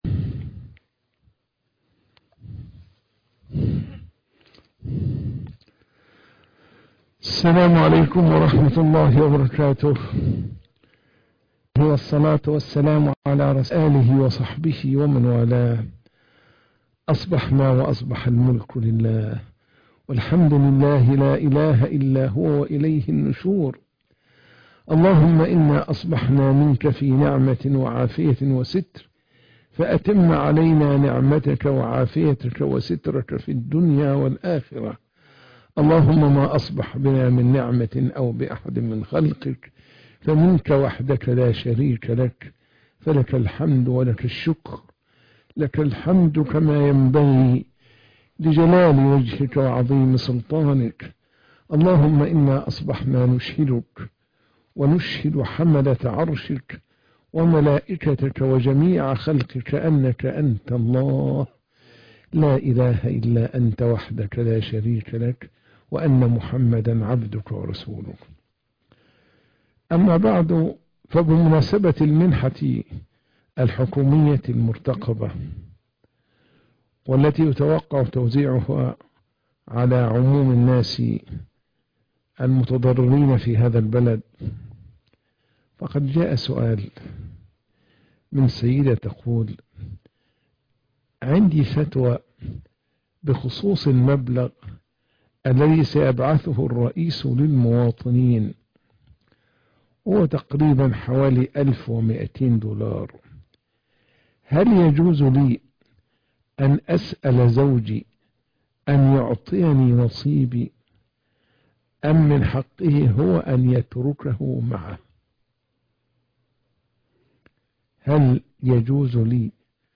فتاوى المسلم